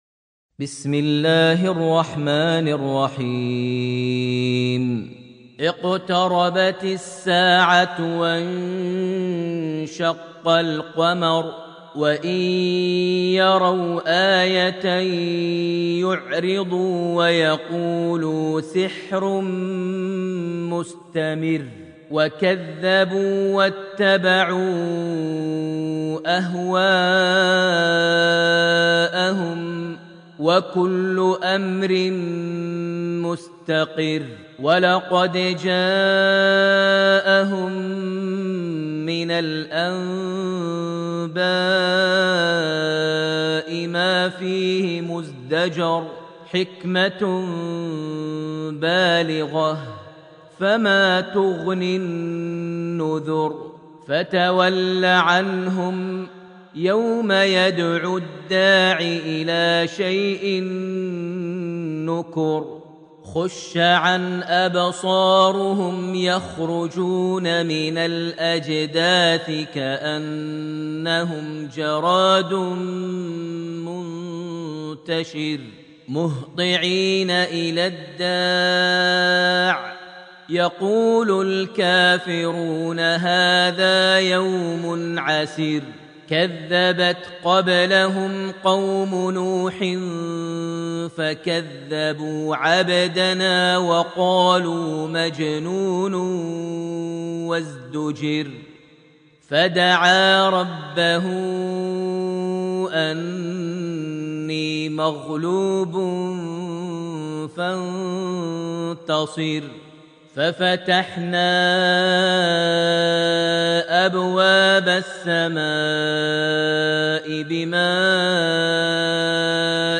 Surah Al-Qamar > Almushaf > Mushaf - Maher Almuaiqly Recitations